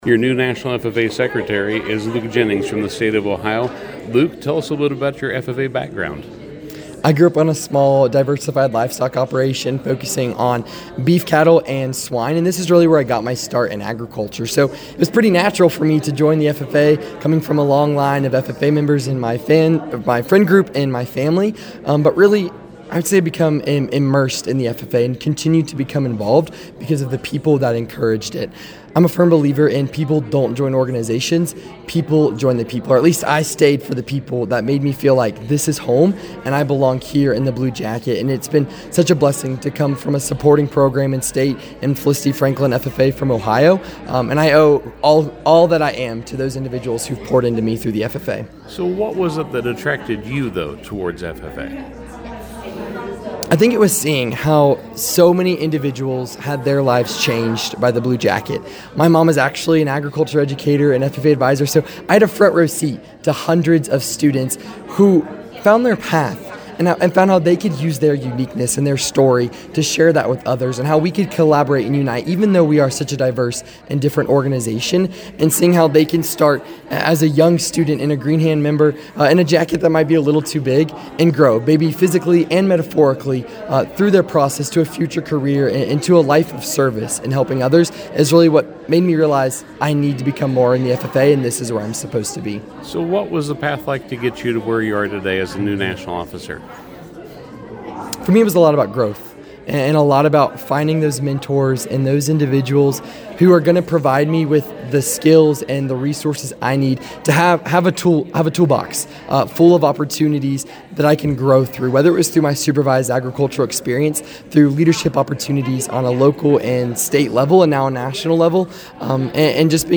By Published On: October 26th, 20241.4 min readCategories: Convention Audio